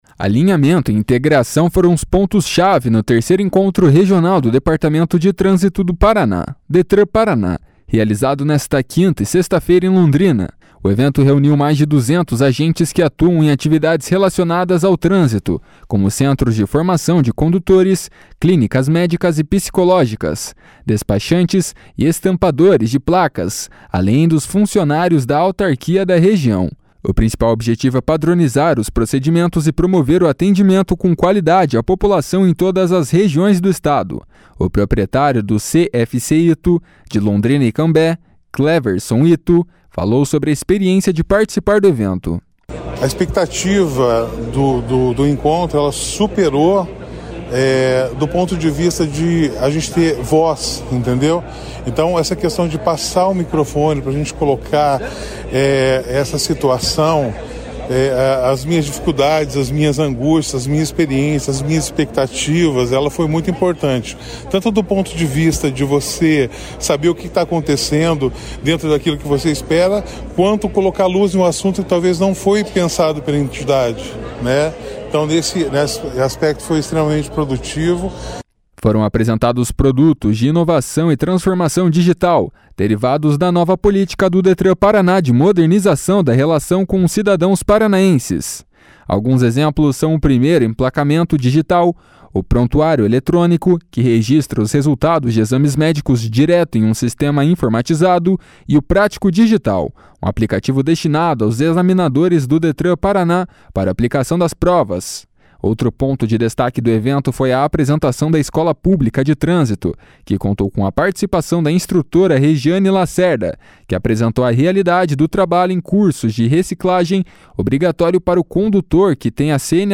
ENCONTRO REGIONAL DO DETRAN.mp3